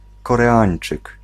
Ääntäminen
Ääntäminen US Tuntematon aksentti: IPA : /kəˈɹɪən/ Haettu sana löytyi näillä lähdekielillä: englanti Käännös Ääninäyte Adjektiivit 1. koreański {m} Erisnimet 2. koreański {m} Substantiivit 3.